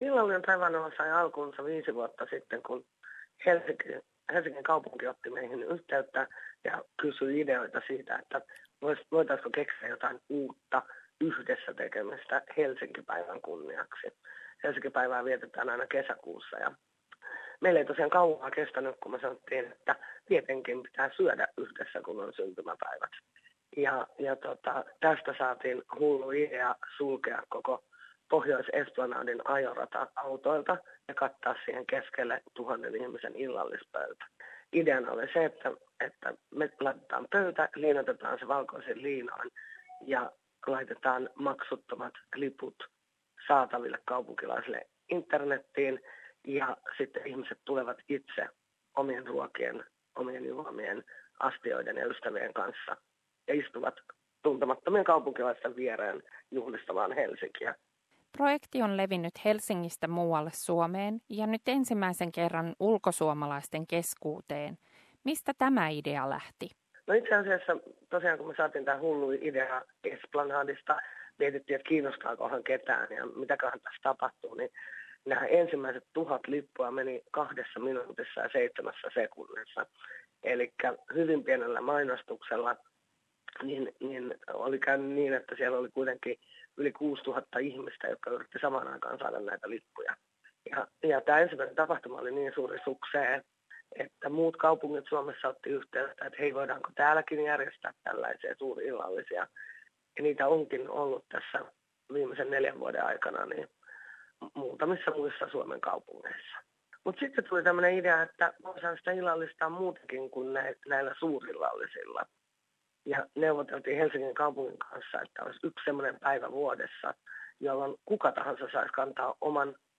Haastattelimme